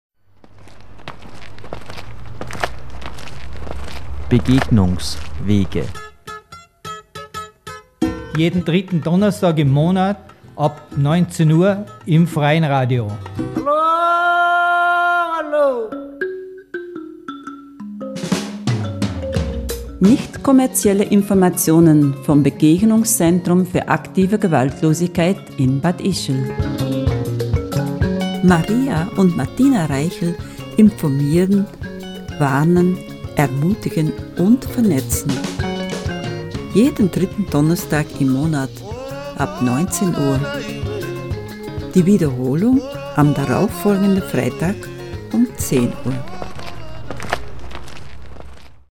Sendungstrailer
BEGEGNUNGSWEGE-TRAILER-NEU-NEU-LANG-3-Donnerstag.mp3